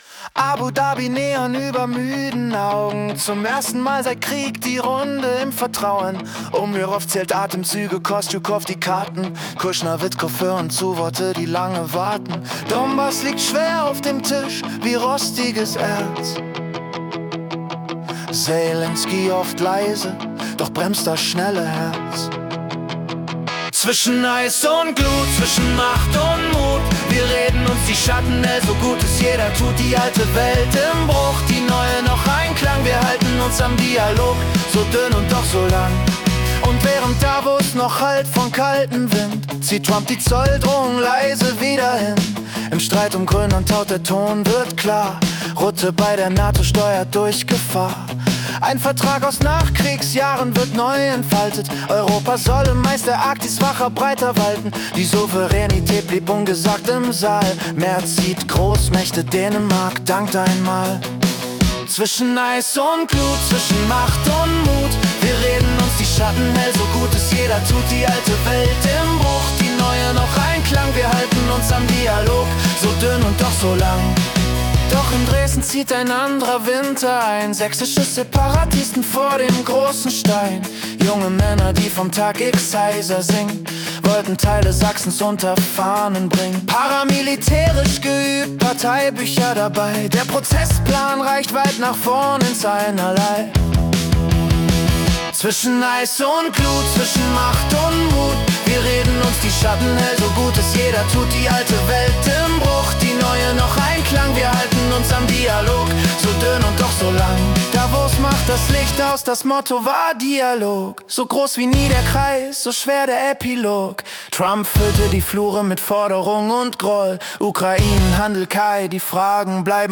Januar 2026 als Singer-Songwriter-Song interpretiert.